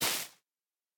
Minecraft Version Minecraft Version snapshot Latest Release | Latest Snapshot snapshot / assets / minecraft / sounds / block / sponge / step2.ogg Compare With Compare With Latest Release | Latest Snapshot
step2.ogg